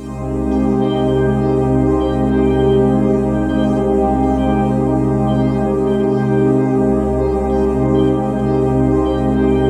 SEQ PAD02.-L.wav